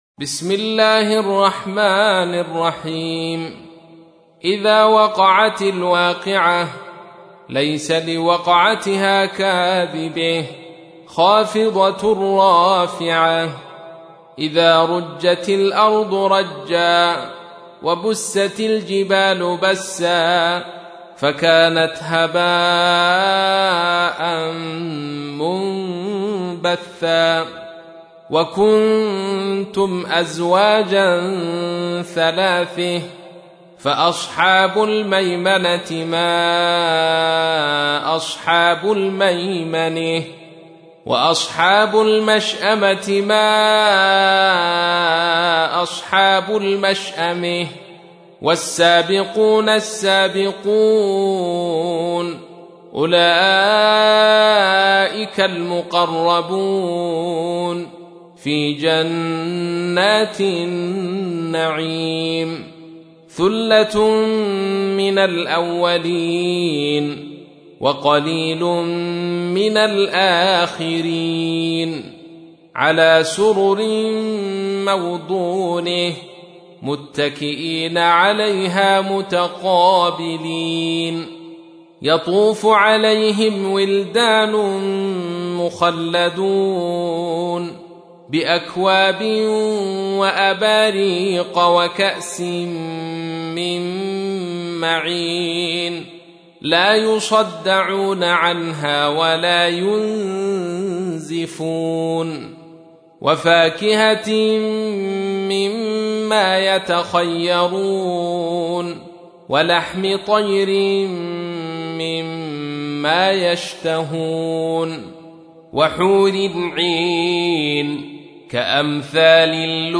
تحميل : 56. سورة الواقعة / القارئ عبد الرشيد صوفي / القرآن الكريم / موقع يا حسين